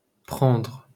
wymowa:
IPA/pʀɑ̃:dʀ/ ?/i